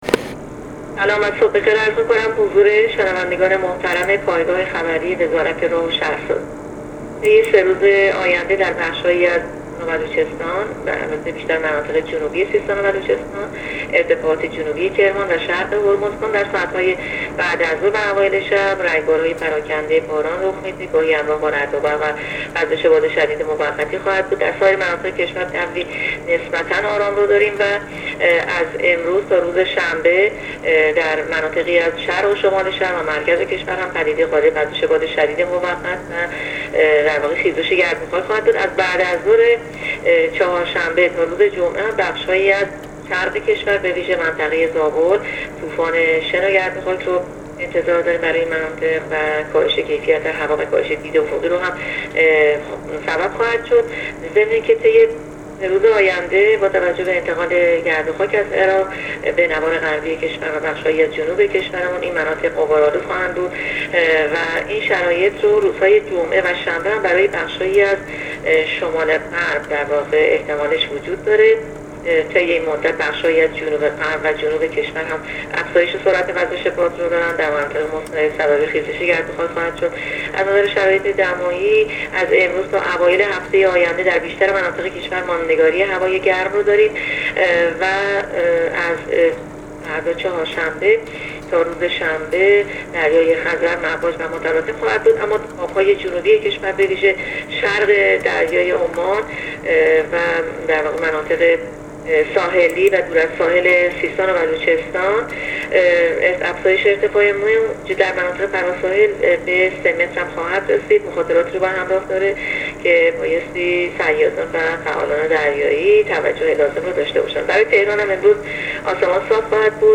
کارشناس سازمان هواشناسی در گفت‌وگو با رادیو اینترنتی پایگاه خبری وزارت راه‌ و شهرسازی، آخرین وضعیت آب‌وهوای کشور را اعلام کرد.
گزارش رادیو اینترنتی پایگاه خبری از آخرین وضعیت آب‌وهوای هفتم تیرماه؛